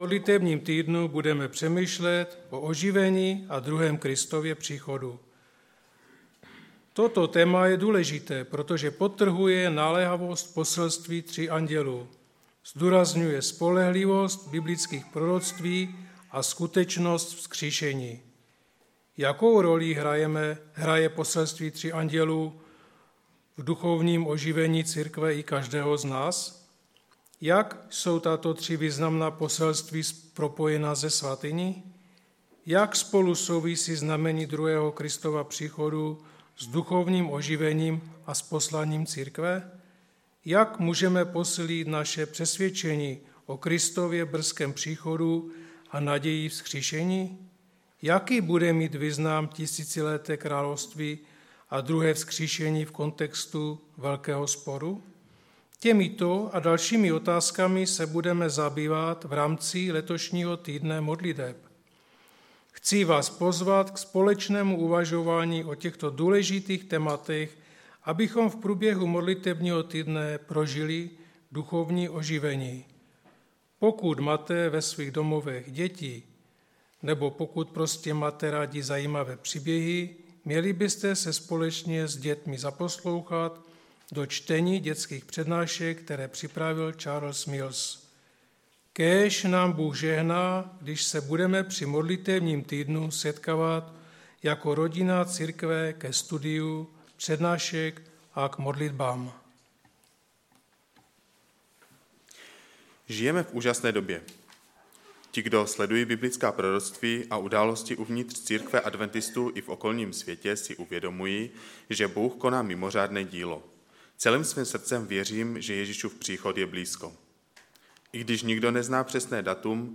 Sbor Ostrava-Radvanice. Shrnutí přednášky začíná kolem 14-té minuty.